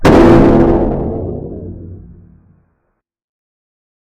explosion2.ogg